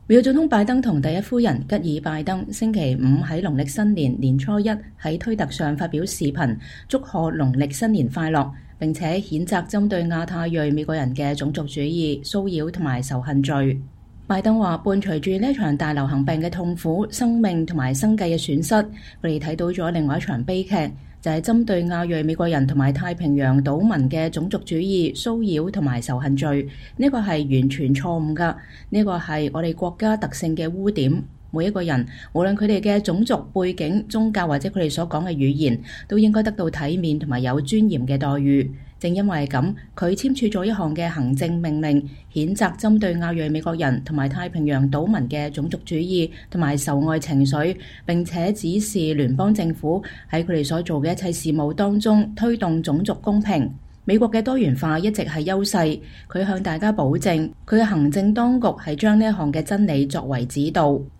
美國總統和第一夫人發表視頻祝賀農曆新年